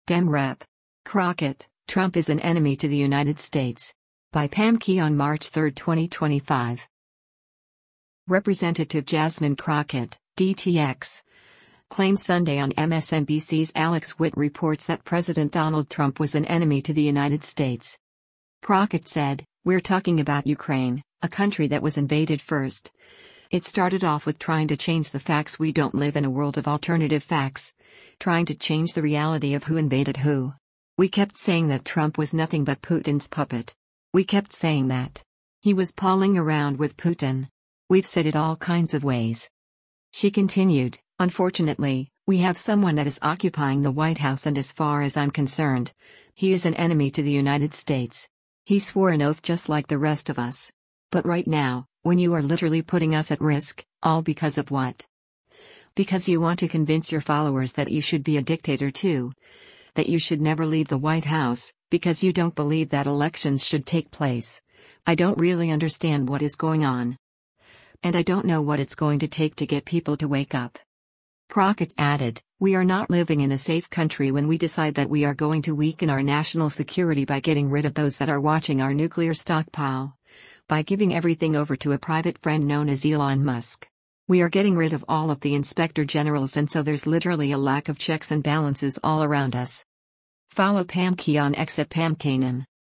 Representative Jasmine Crockett (D-TX) claimed Sunday on MSNBC’s “Alex Witt Reports” that President Donald Trump was “an enemy to the United States.”